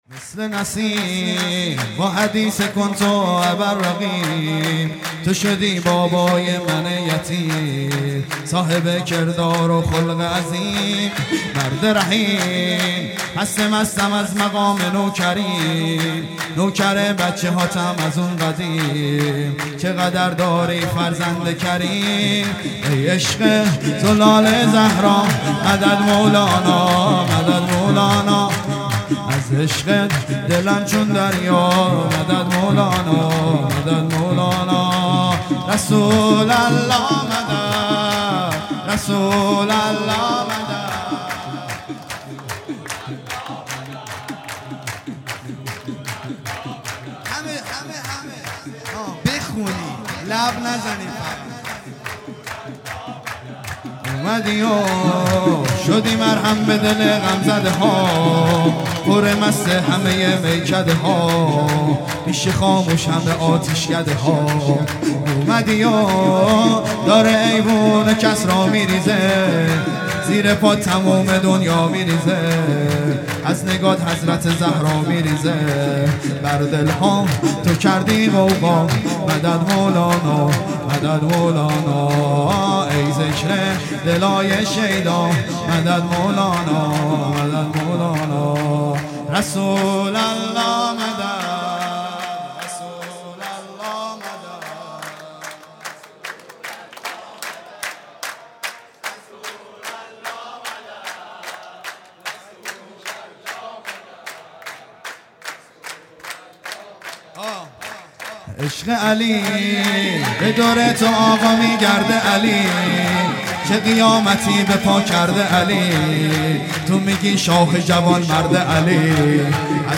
سرود
ولادت پیامبر (ص) و امام صادق (ع) | ۳ آذر ۱۳۹۷